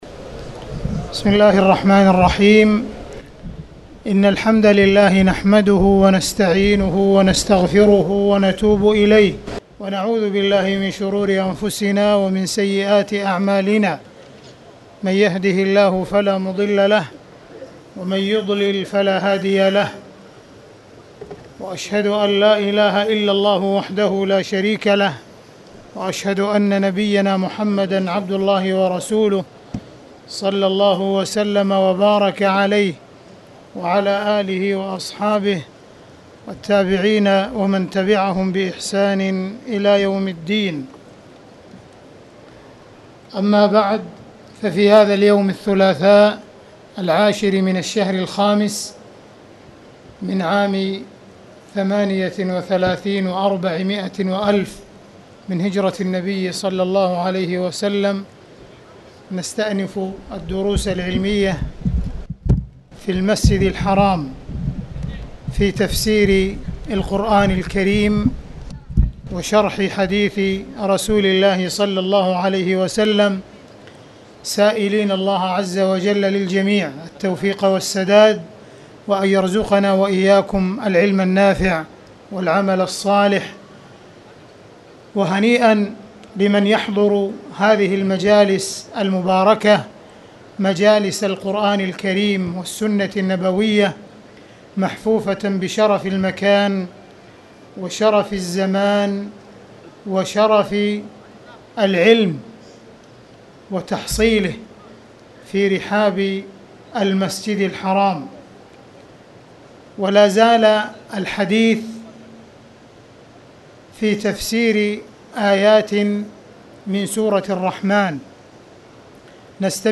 تاريخ النشر ١٠ جمادى الأولى ١٤٣٨ هـ المكان: المسجد الحرام الشيخ: معالي الشيخ أ.د. عبدالرحمن بن عبدالعزيز السديس معالي الشيخ أ.د. عبدالرحمن بن عبدالعزيز السديس سورة الرحمن -باب الحيض